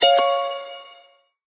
13. notification7